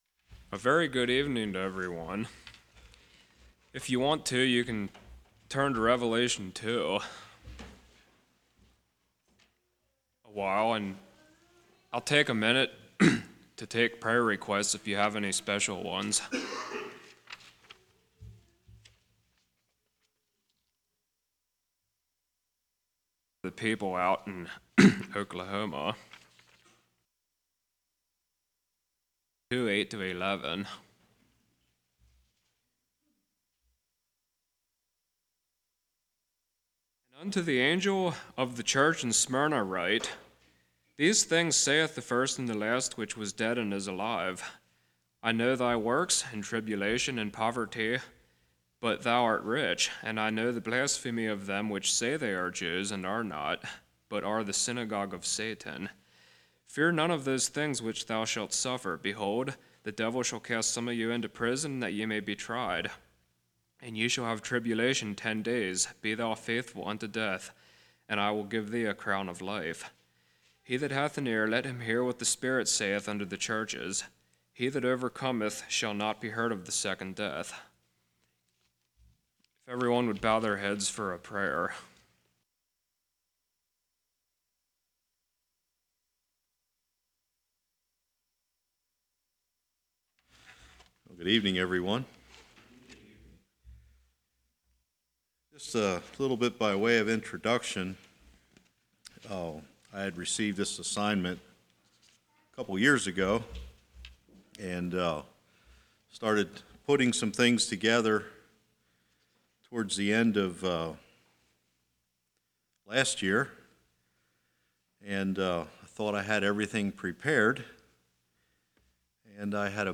Service Type: Winter Bible Study